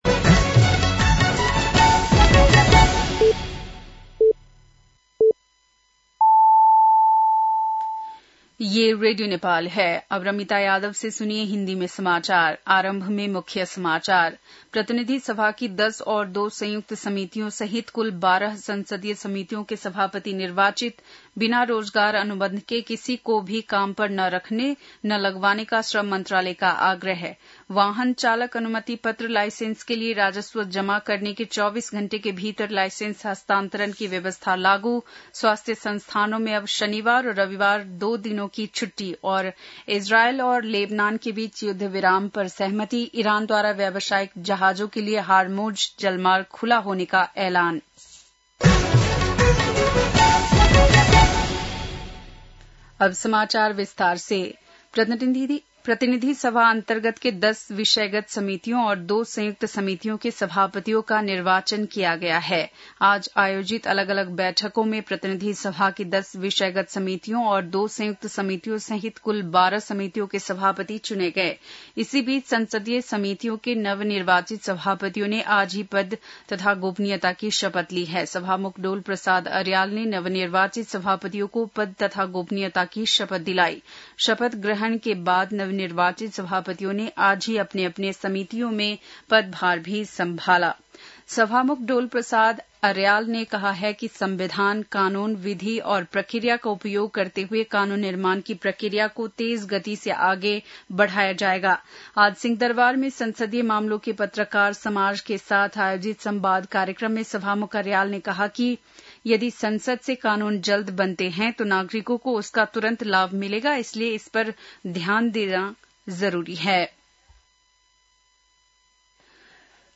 बेलुकी १० बजेको हिन्दी समाचार : ४ वैशाख , २०८३
10.-pm-nepali-news-.mp3